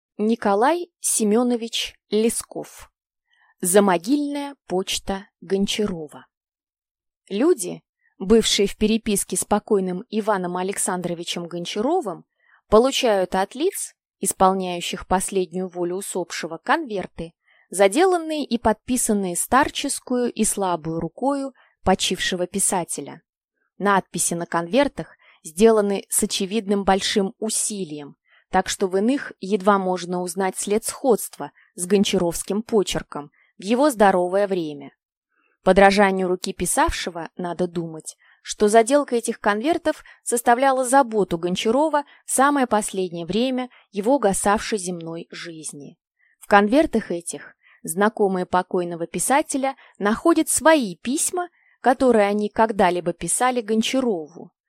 Аудиокнига Замогильная почта Гончарова | Библиотека аудиокниг